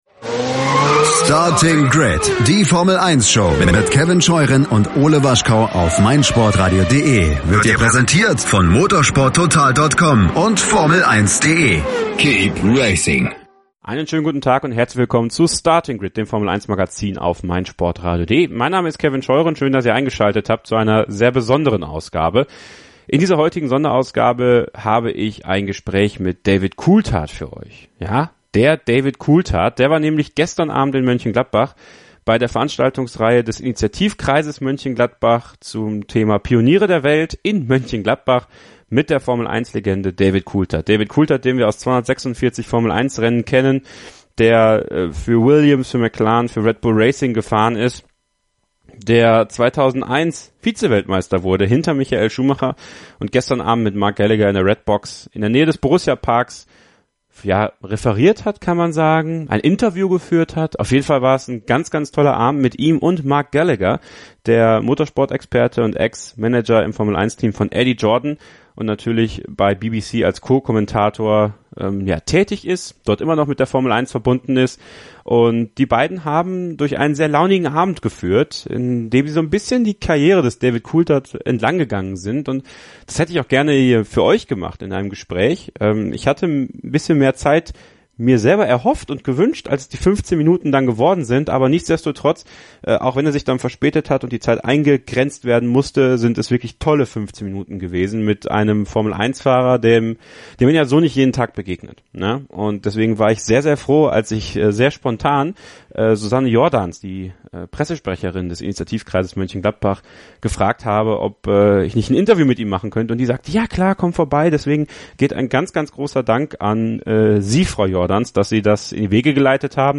Exklusivgespräch